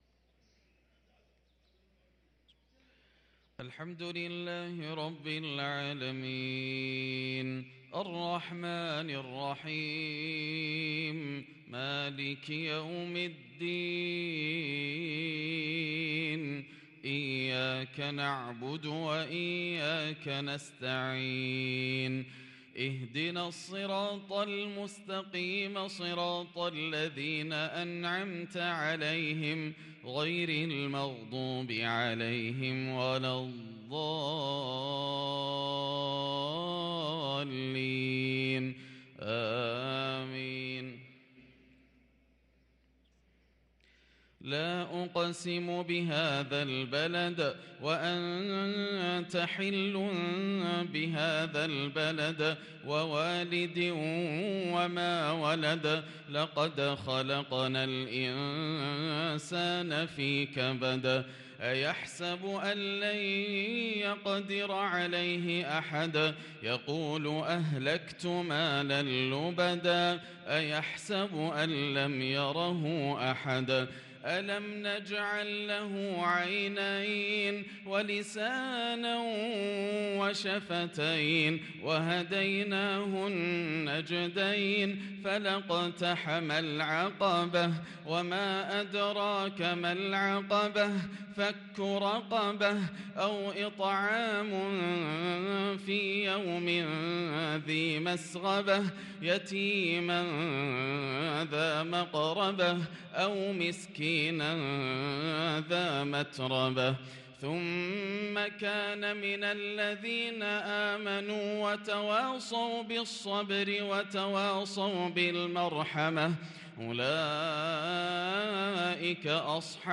صلاة المغرب للقارئ ياسر الدوسري 4 جمادي الآخر 1444 هـ
تِلَاوَات الْحَرَمَيْن .